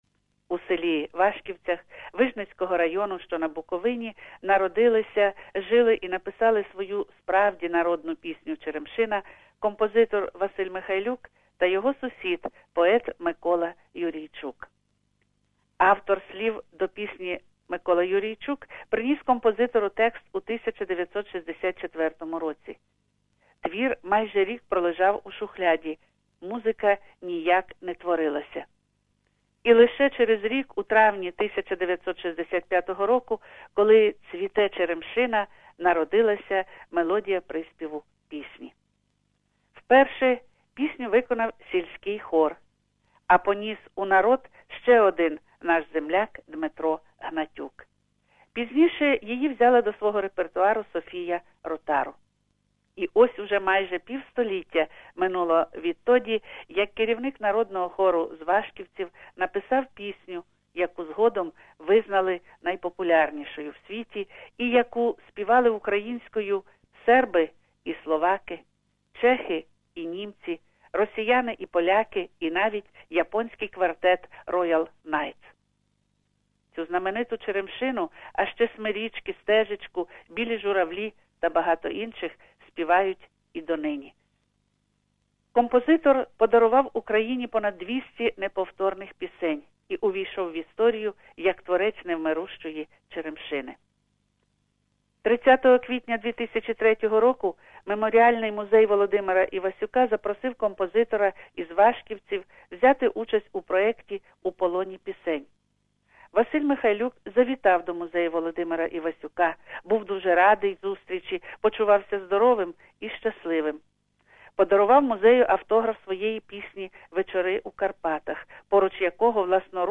Дещо на згадку з архіву СБС українською...